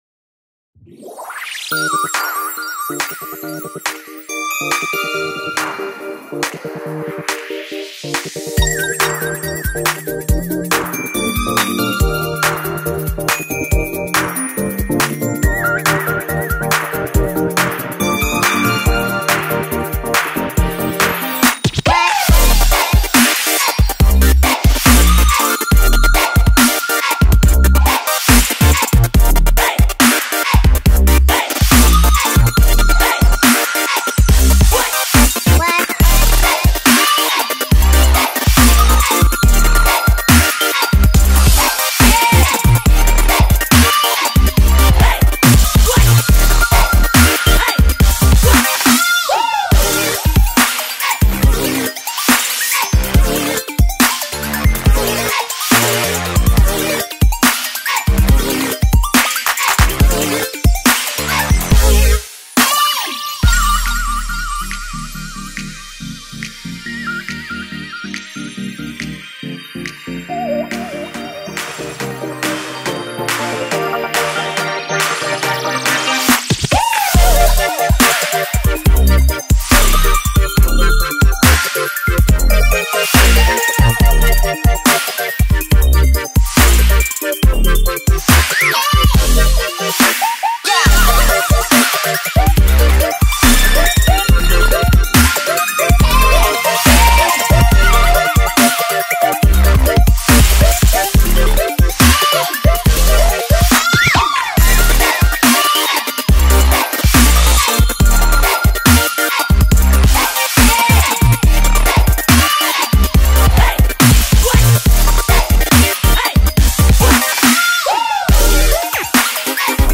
BPM70-140